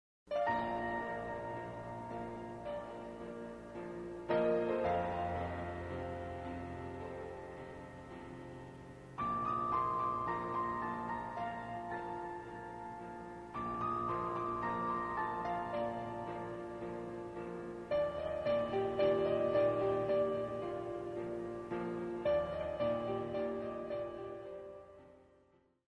37 Piano Selections in this Beginnners Level II CD.